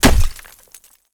poly_explosion_ice.wav